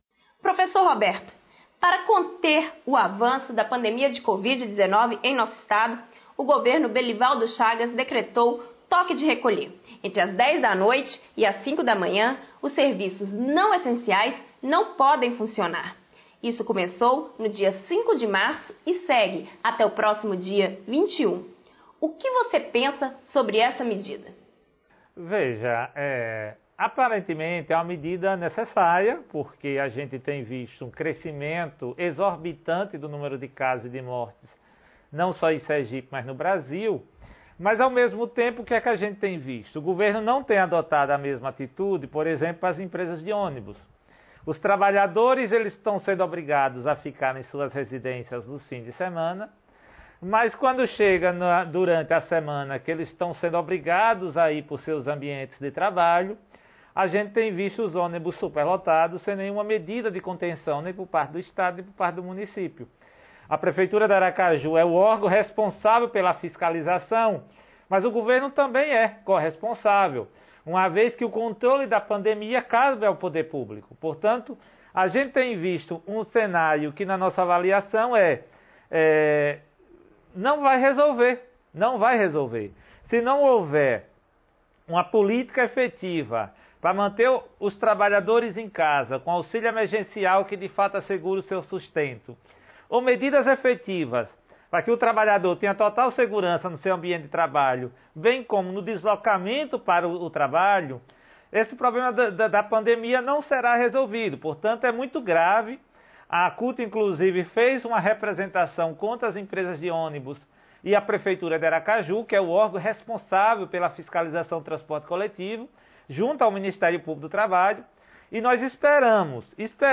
Entrevista concedida ao Programa TV Sintese sobre as medidas de controle a pandemia, vacinação em Sergipe e o retornoàs aulas presenciais.